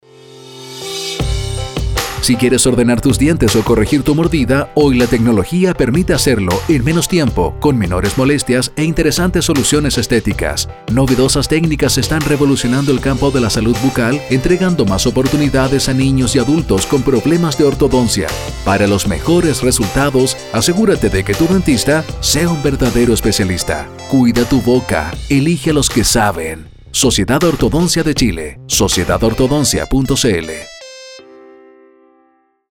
13 Jul Nueva Frase Radial Radio ADN